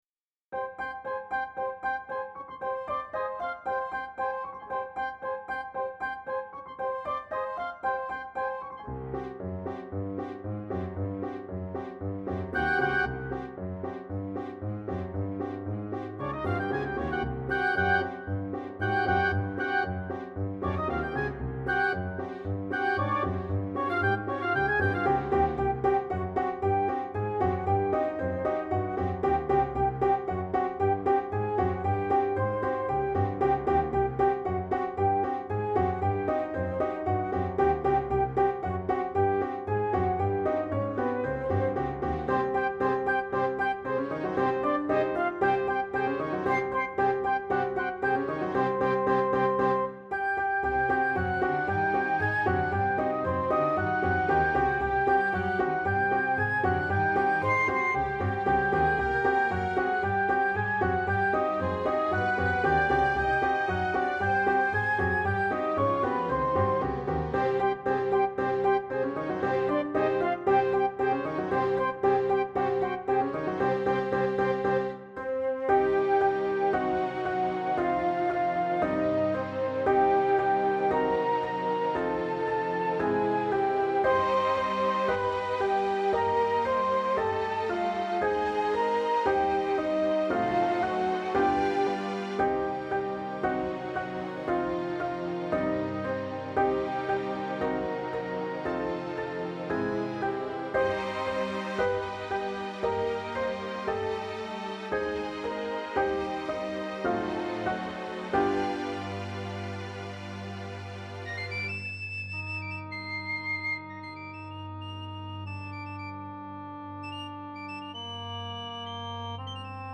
backing track